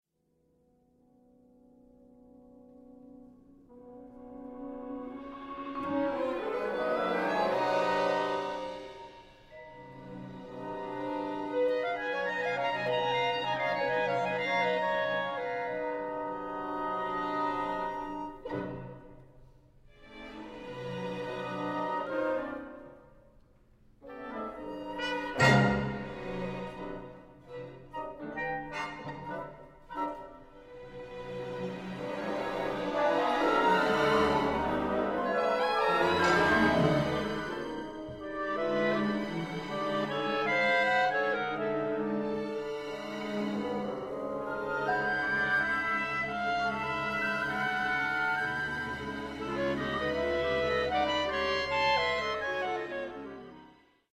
for clarinet trio and orchestra
clarinet